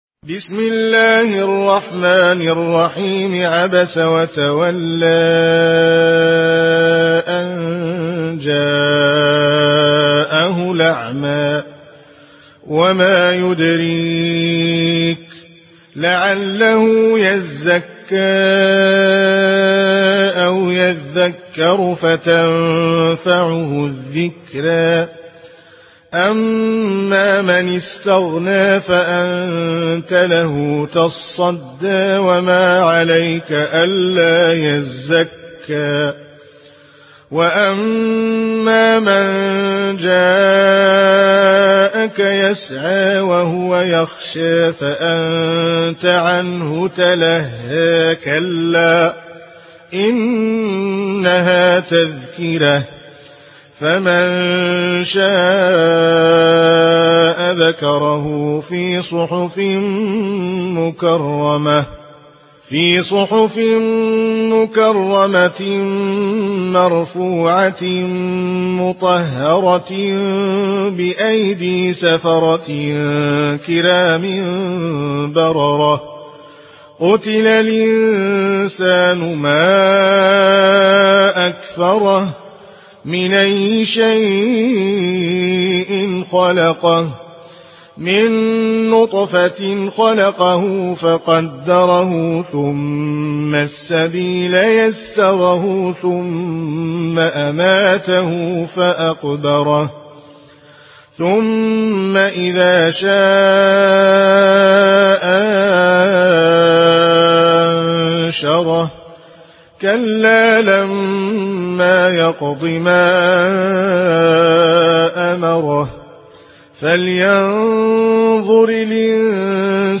Warsh An Nafi narration
Murattal Warsh An Nafi